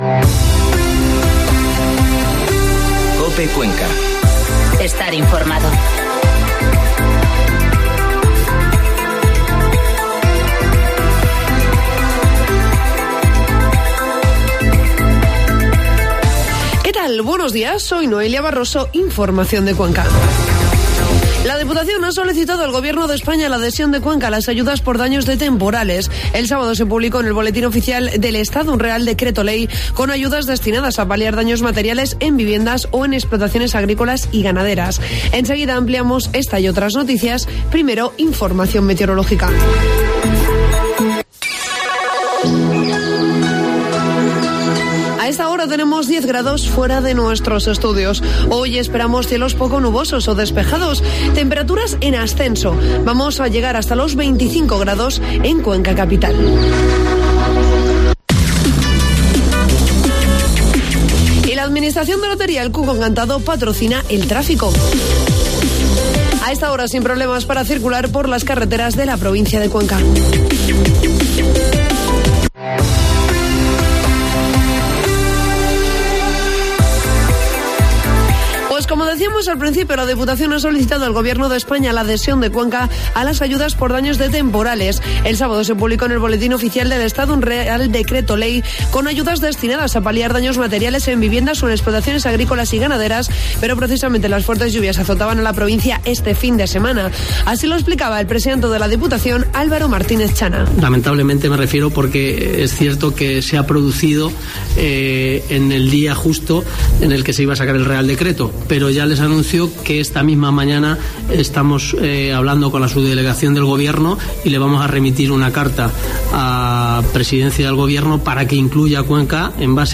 Informativo matinal COPE Cuenca 24 de septiembre